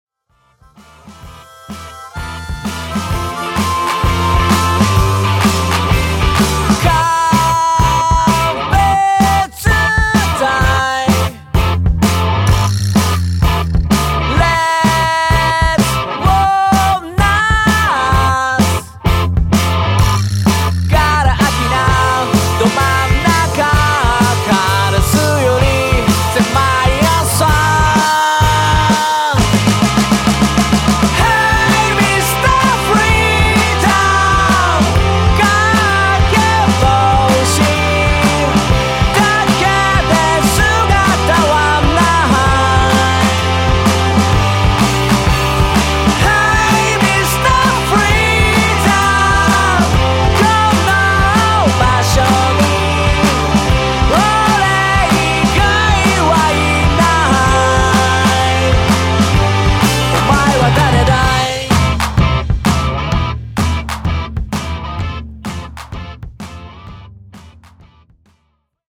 ６０年代ブリティッシュの匂いが強烈なロックバンド。
ボーカルも演奏も勢いがあってロックらしいバンドサウンドのお手本みたい。
ヴォーカルの声質がJohn LydonとHeavy Metal Kidsをミックスしたような感じで、昔なら福岡や京都から出てきそうなカリスマ的カッコよさを持ったサウンドです。ドラムスの人がキーボード担当というのもおもしろい。